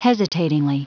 Prononciation du mot hesitatingly en anglais (fichier audio)
Prononciation du mot : hesitatingly
hesitatingly.wav